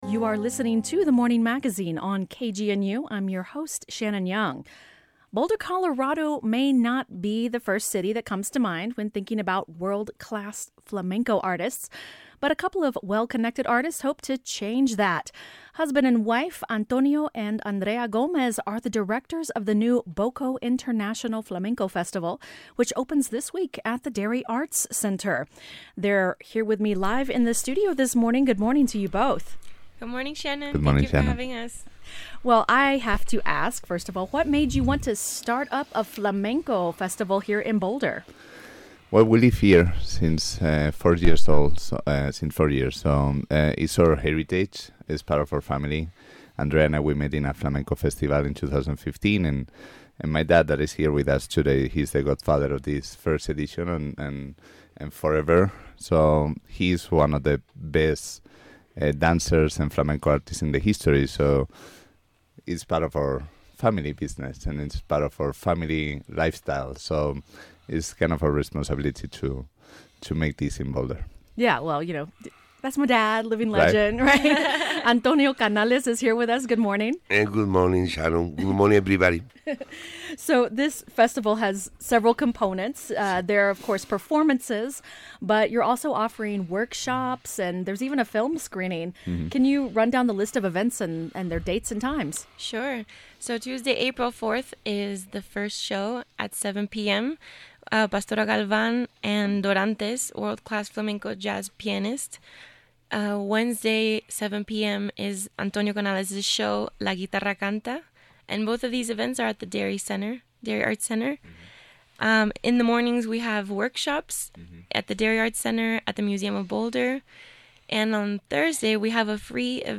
KGNU Broadcast Live On-Air